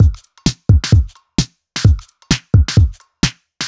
Index of /musicradar/uk-garage-samples/130bpm Lines n Loops/Beats
GA_BeatnPercE130-08.wav